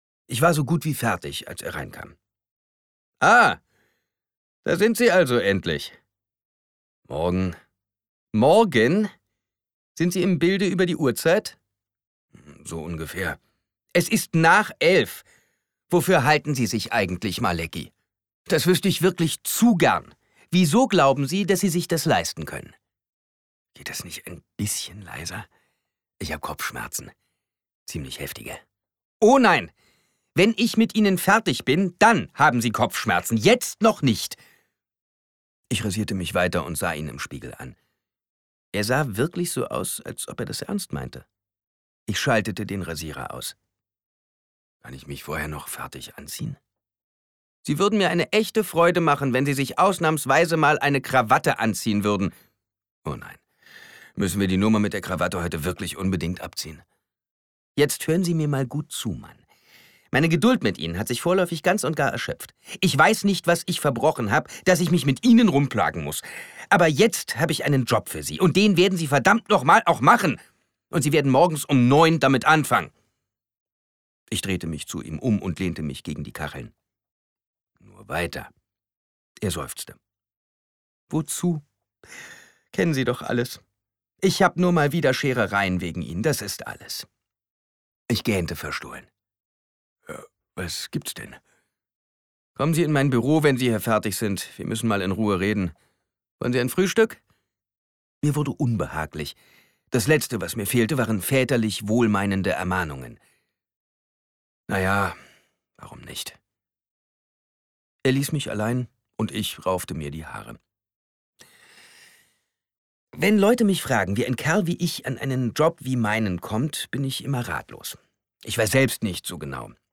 Krimi . .